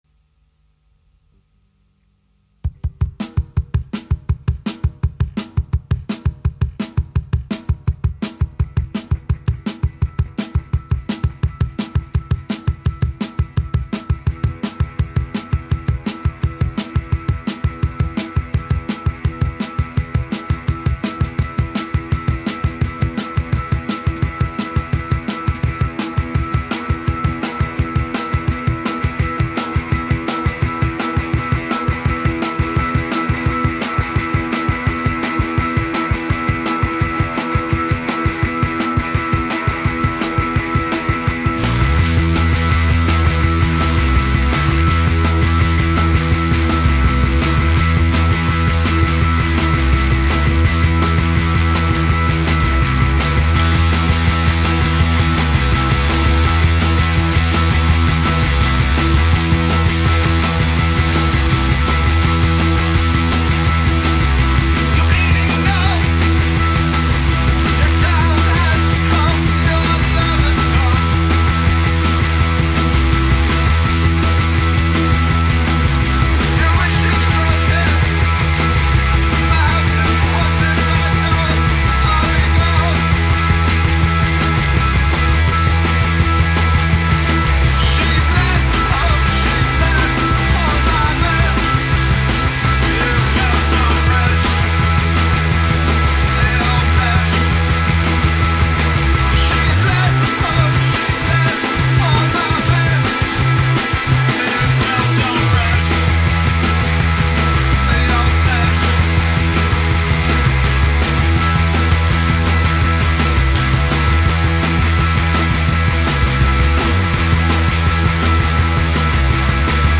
went into the studio
of angry and scorching punk nuggets